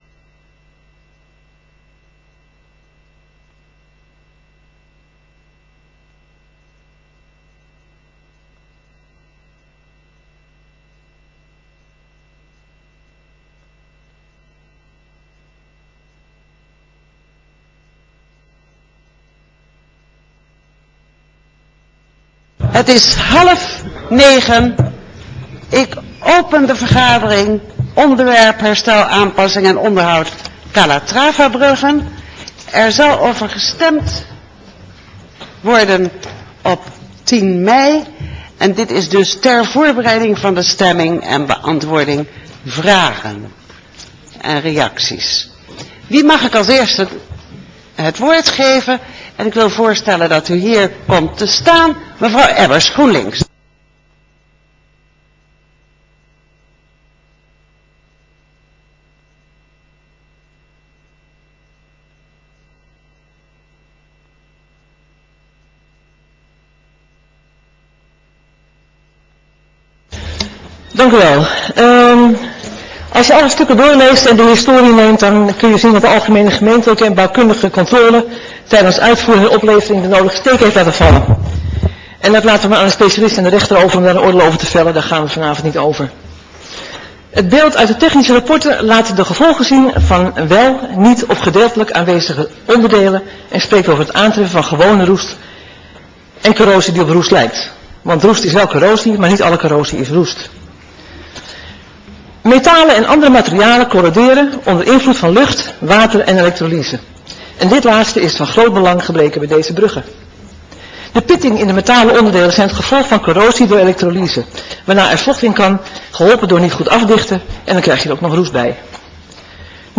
Raadssessie: Calatravabruggen
Locatie: Raadzaal